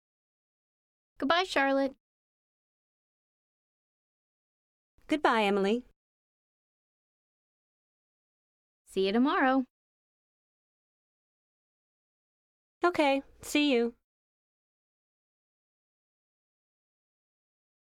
1-6 Page 20 Conversation.mp3